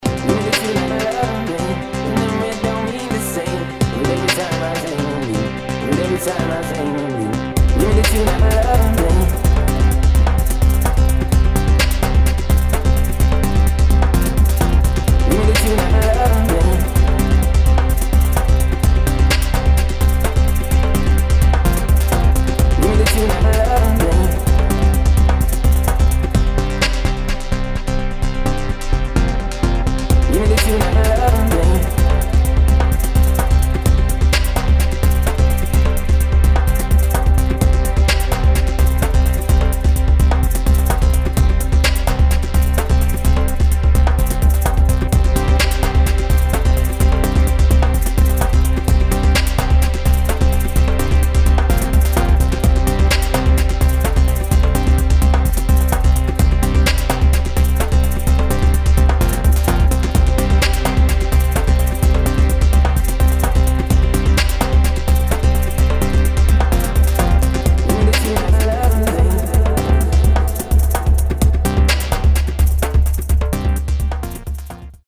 [ BASS ]